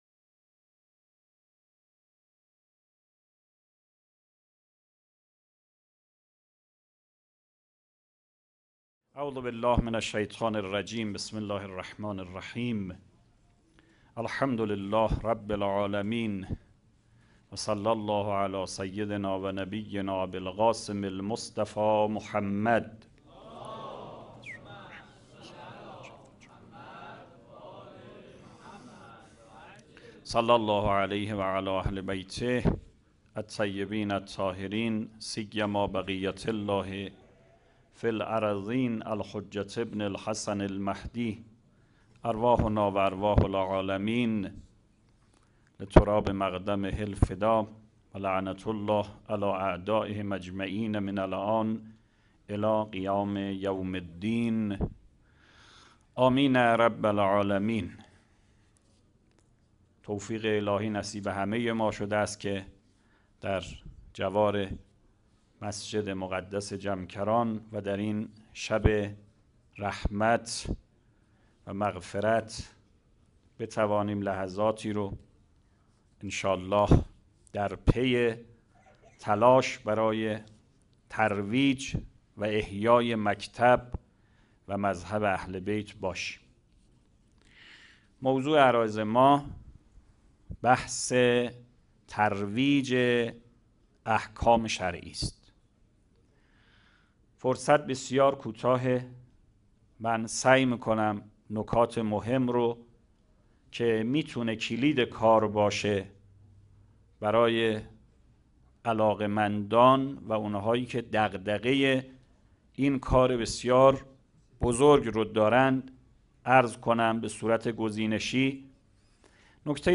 سخنرانی | تروج احکام شرعی
چهارمین همایش ملی هیأت‌های محوری و برگزیده کشور | شهر مقدس قم - مجتمع یاوران مهدی (عج)